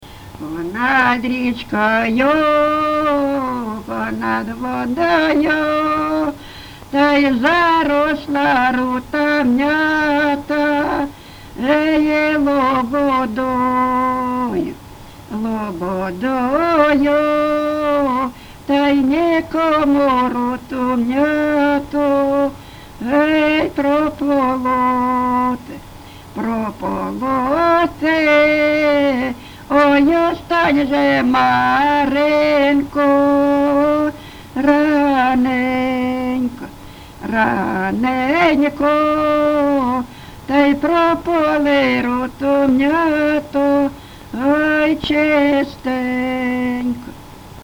ЖанрПетрівчані
Місце записус. Привілля, Словʼянський (Краматорський) район, Донецька обл., Україна, Слобожанщина